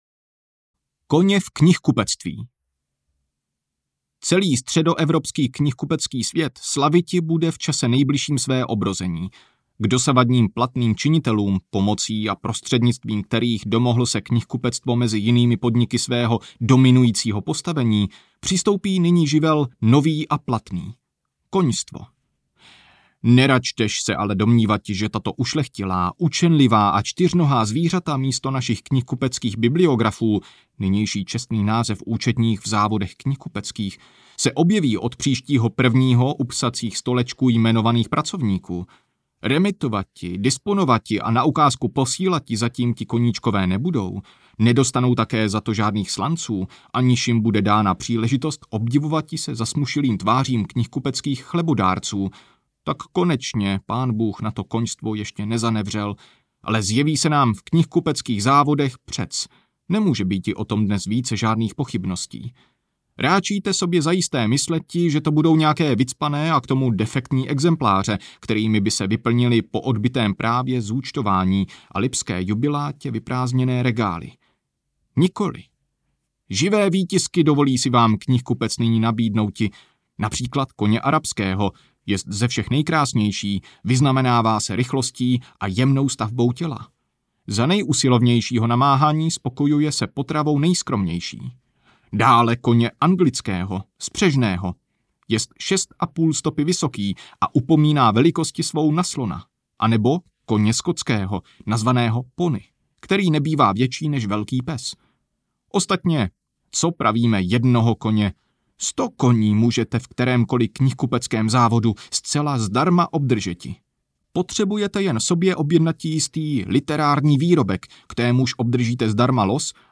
Z ptačí perspektivy audiokniha
Ukázka z knihy